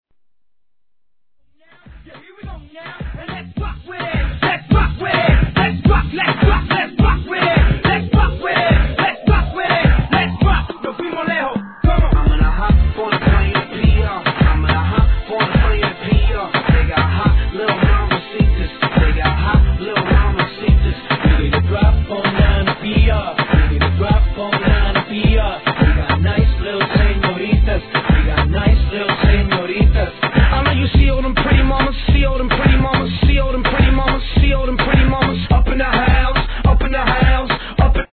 1. HIP HOP/R&B
(BPM 105)